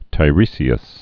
(tī-rēsē-əs)